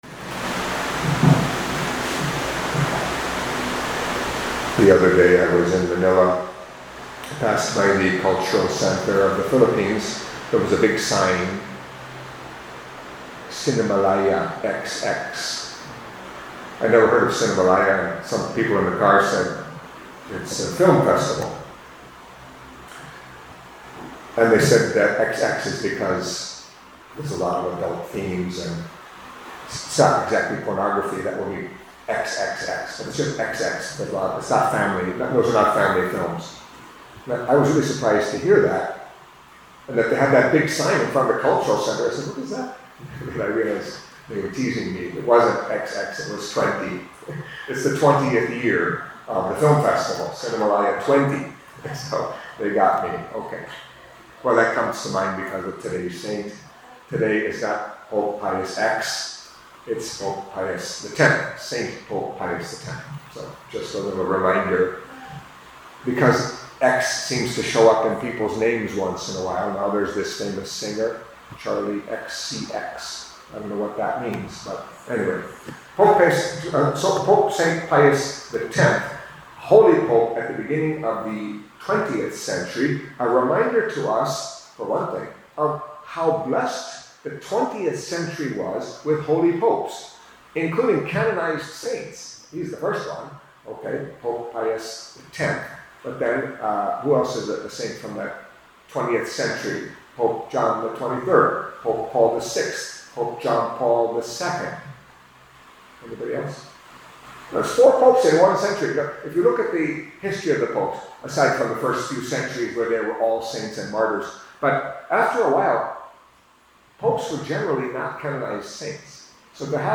Catholic Mass homily for Wednesday of the Twentieth Week in Ordinary Time